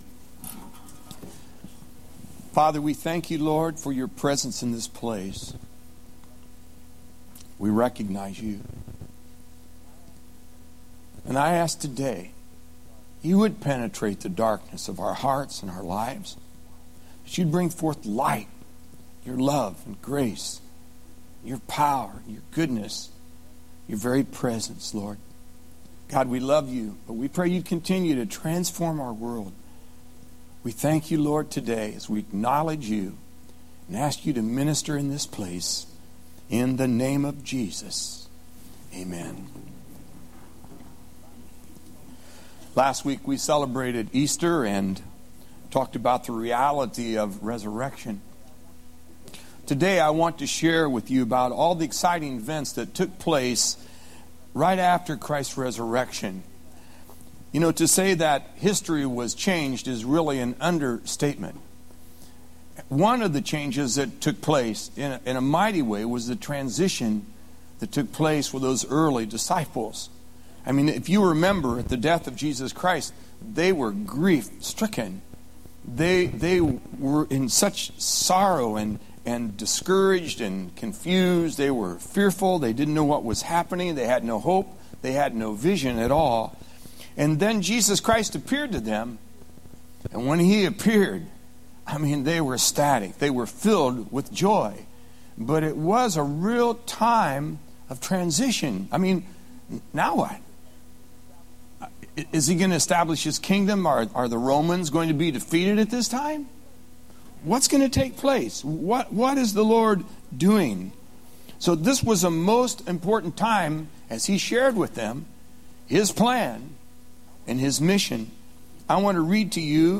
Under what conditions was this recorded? Clearwater Fl. 04/15/07-AM 1st Service